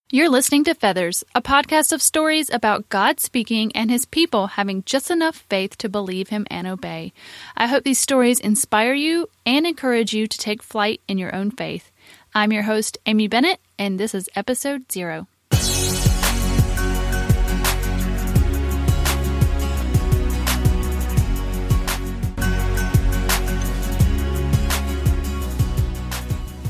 Feathers-Intro.mp3